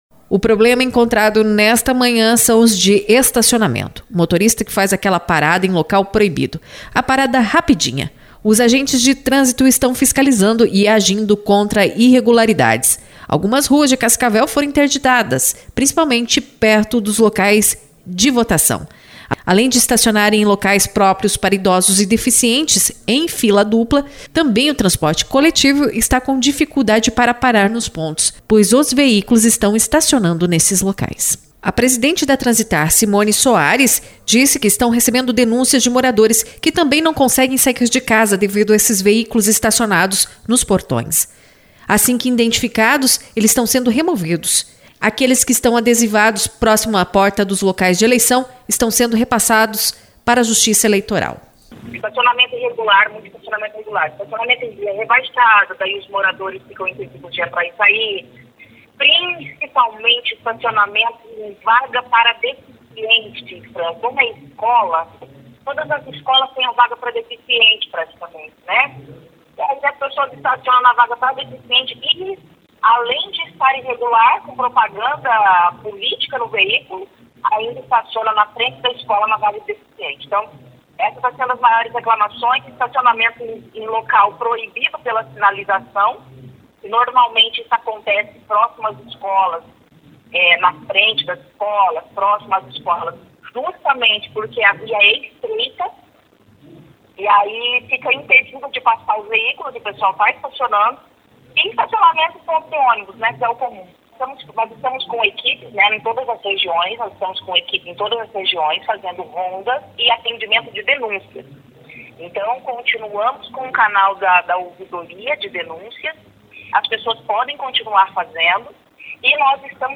A presidente da Transitar , Simoni Soares, falou nesta manhã de domingo (02) à CBN Cascavel sobre os veículos que estão fazendo o famoso "estaciomento rapidinho" bloqueando saídas de veículos, além de estacionarem em pontos de ônibus, em vagas de deficientes e idosos.